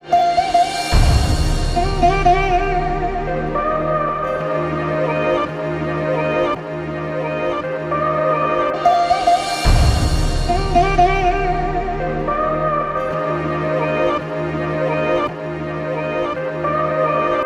Gully-Loops-Svarg-Synth-Loop-BPM-110-Eb-Maj.wav